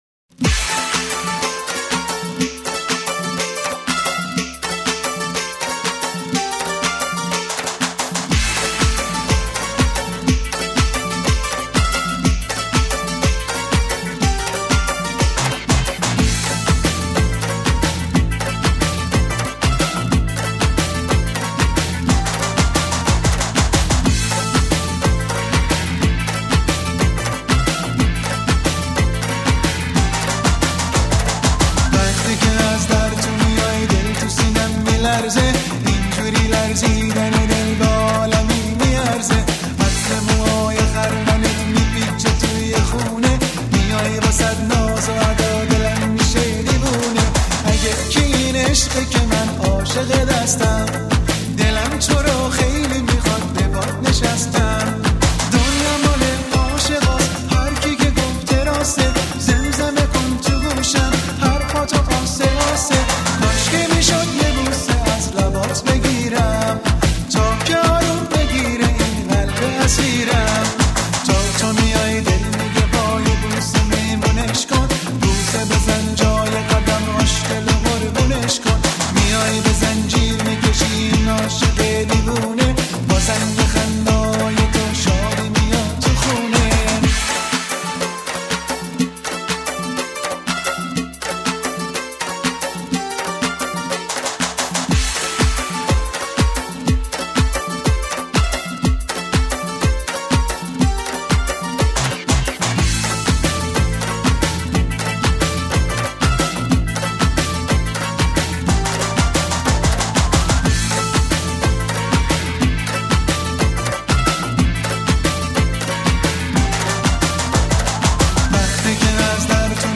07_-_vaghti_az_dar_to_miyai_pop.mp3